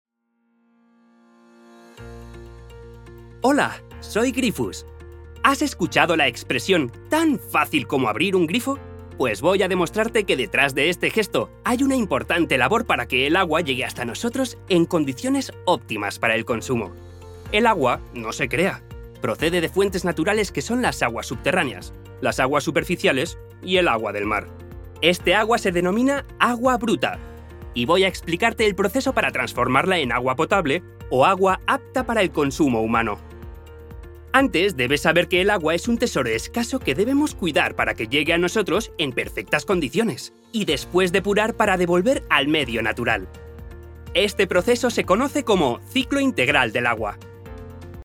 Soy locutor profesional. Mi voz, fresca, juvenil y enérgica, puede hacerte levantar del sillón en un día triste, pero también puede calmarte con un tono amable y relajante.
Sprechprobe: Industrie (Muttersprache):
My fresh, volatile and energetic voice can jump you up in a sad day, and also calm you down with a friendly and relaxing tone. My voice can be adapted to any need, which is why I like to understand what the project requires and transmit it with my voice.